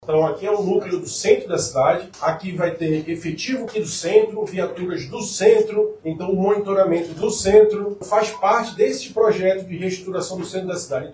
Essa nova estrutura visa reforçar a presença da guarda na região e proporcionar atendimento eficiente para a população, explica o secretário municipal de Segurança Pública e Defesa Social de Manaus, Alberto Siqueira Neto.
Sonora-1-Alberto-Siqueira-Neto.mp3